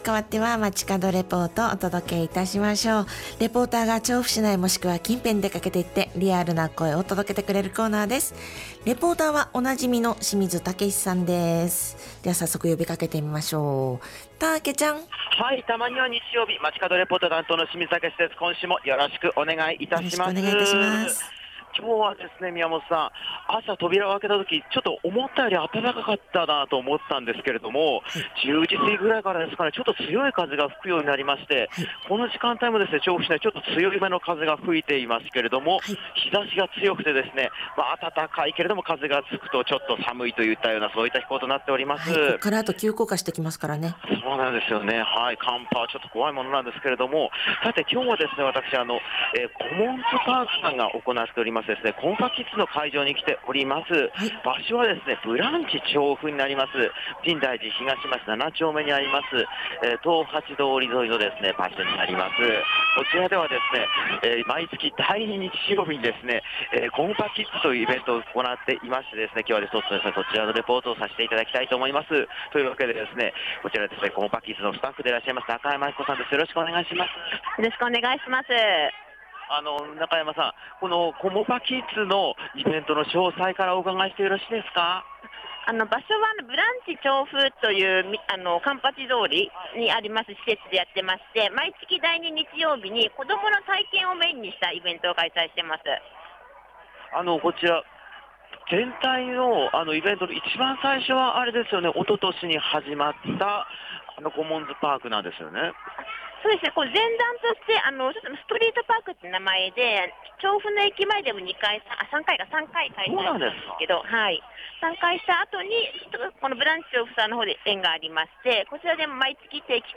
年明けとは思えない暖かさと強い風の空の下からお届けした本日の街角レポートは、
ブランチ調布で開催中の「コモパキッズ」からのレポートです！！
また、レポートのコーナー中にステージで大盛り上がりだったのは、